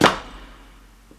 Raw Slapstick.wav